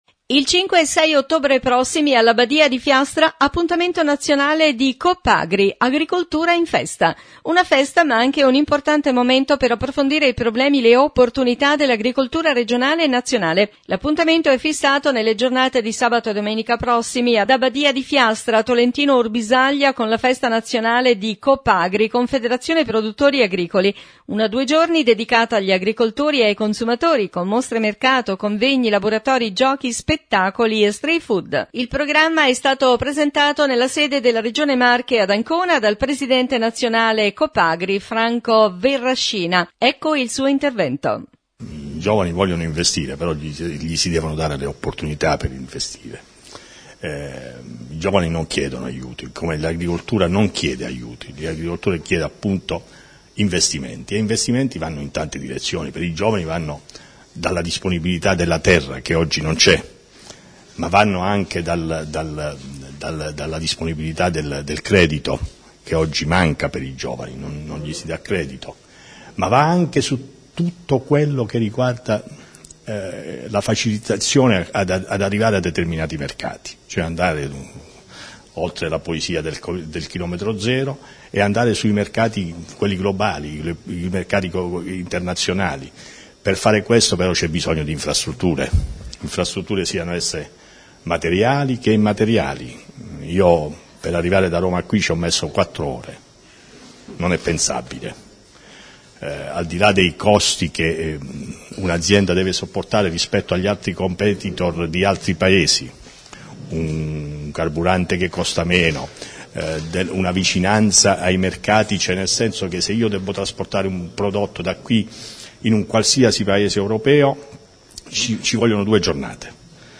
Presentata la manifestazione “Agricoltura in Festa”, l’iniziativa Nazionale della COPAGRI dedicata ad agricoltori e consumatori, che si terrà il 5 e 6 ottobre ad Abbadia di Fiastra Interviste
Anna Casini – Vicepresidente Regione Marche